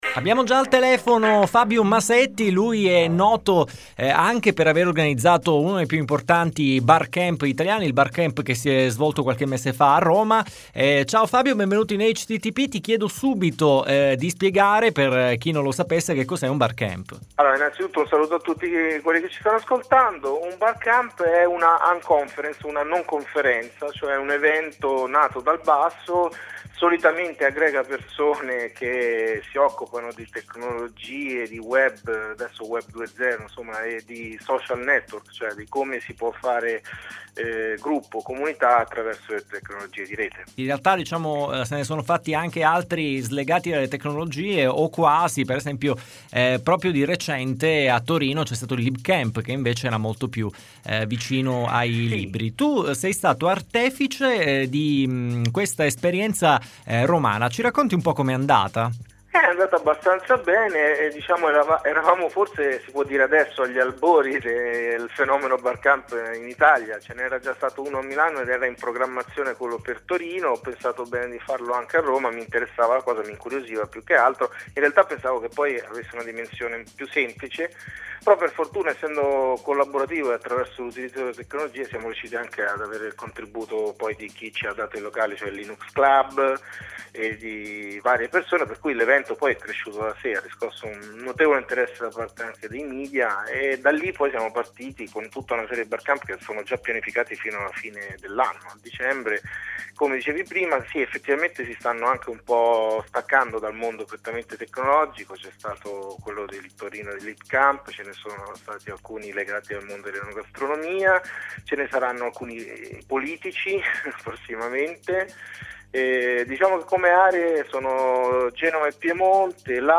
Intervista citata